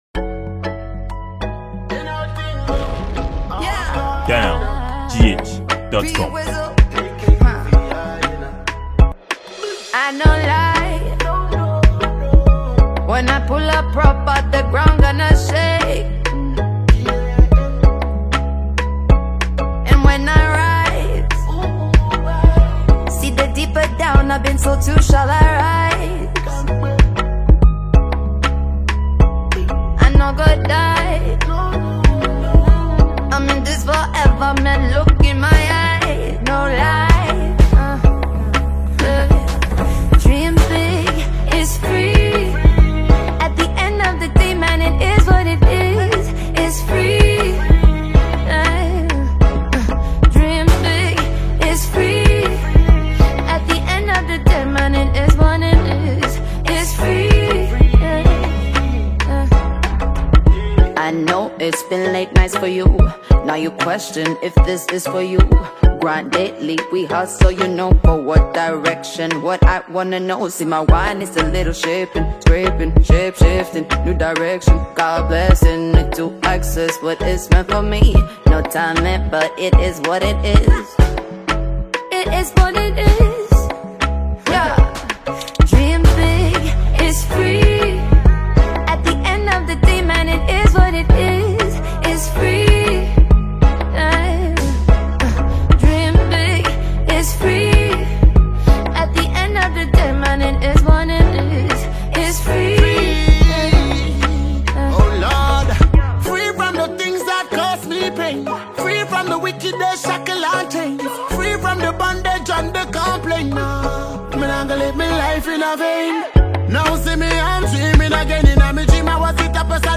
Ghana Music
Ghanaian singer and songwriter
Ghanaian dancehall award winning musician